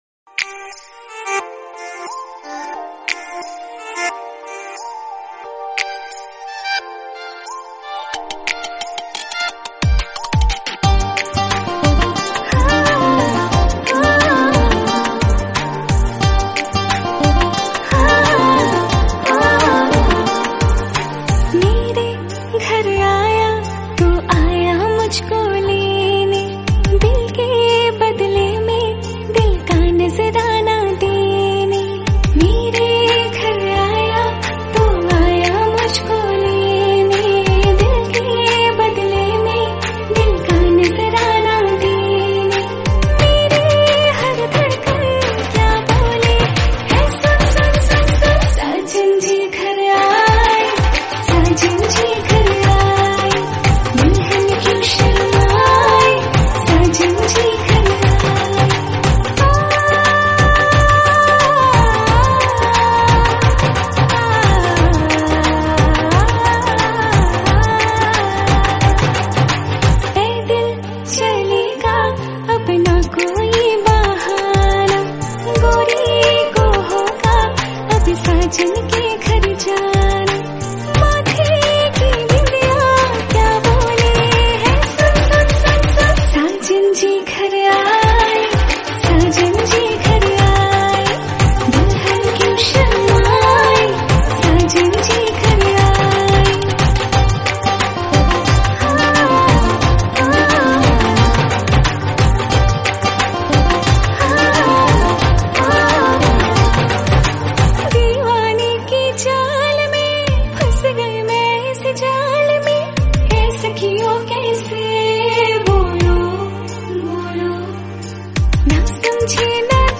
Bollywood Cover Songs